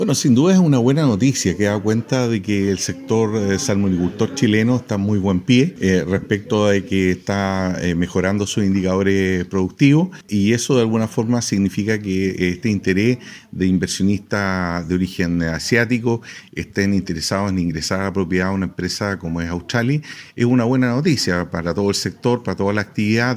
Eduardo Aguilera, director regional de Sernapesca, dijo que es una buena señal respecto a los indicadores productivos de la industria salmonicultora, lo que significa que inversionistas de origen asiáticos se interesen en el país.